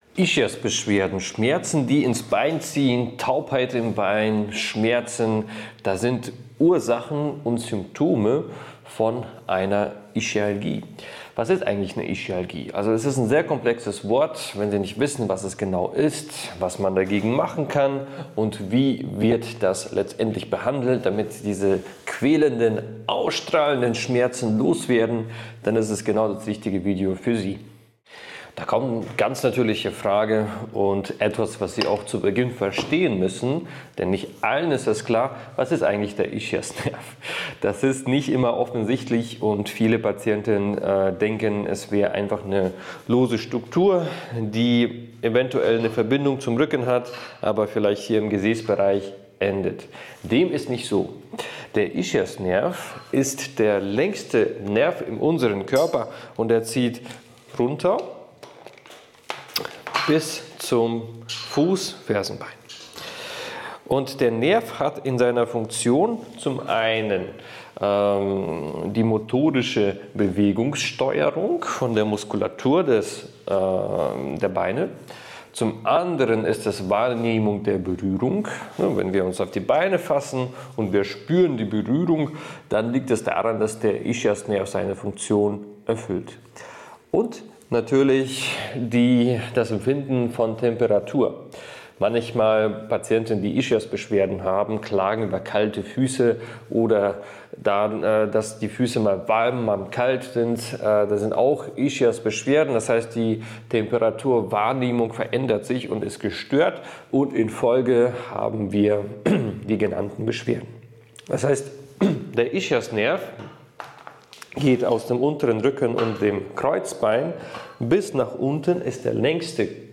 In diesem Gespräch wird umfassend über Ischiasbeschwerden und deren Ursachen, Symptome und Behandlungsmöglichkeiten diskutiert.